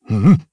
Dakaris-Vox_Attack1_jp.wav